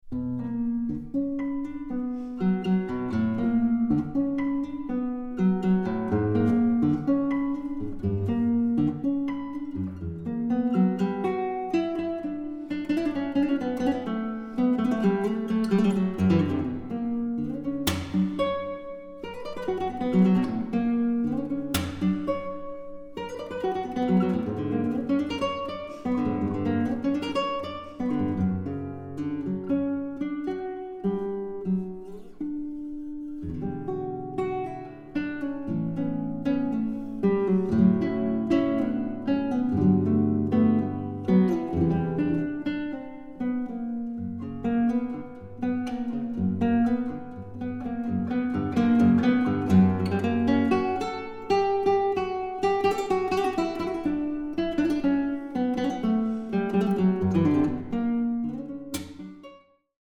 Eine musikalische Collage kanonischer Gitarrenwerke
Gitarre